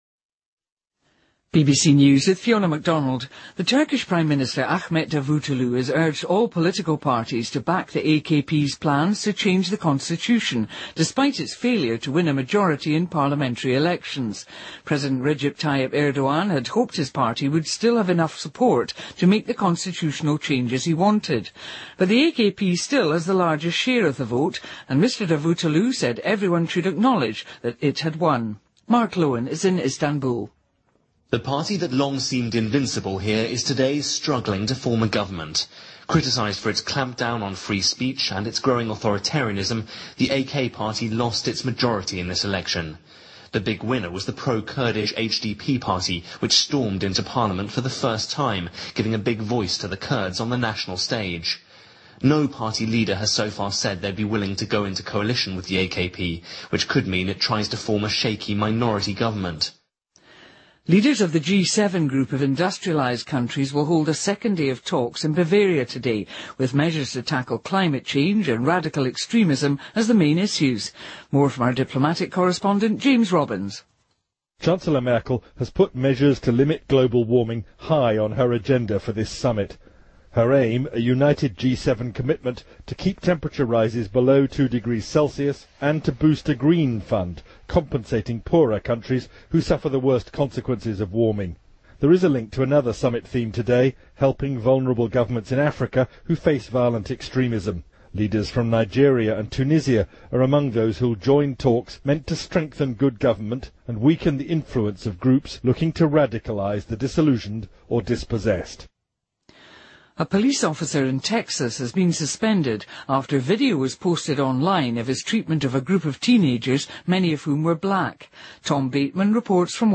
BBC news,七国峰会谈论气候变化和极端主义